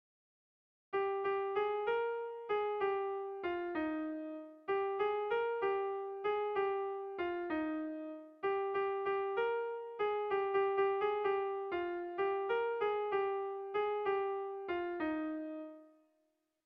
Sehaskakoa